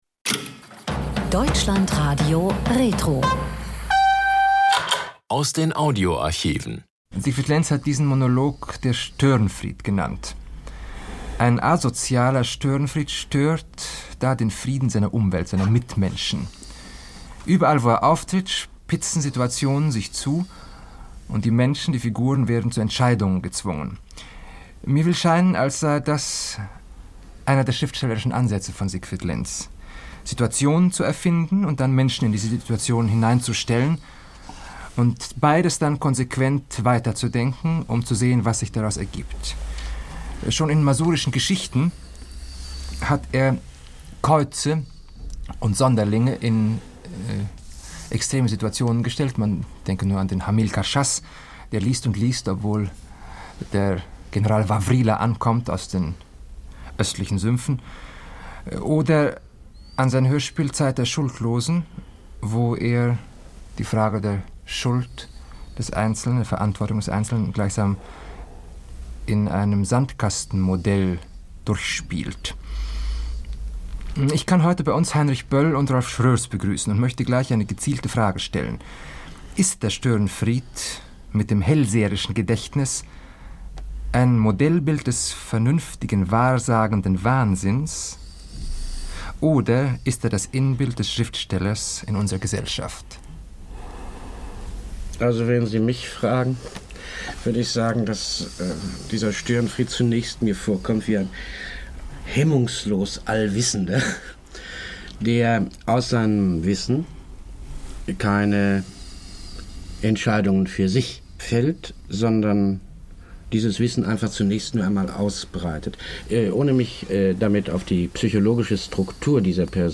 DLF-Gespräch